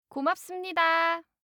알림음 8_고맙습니다2-여자.mp3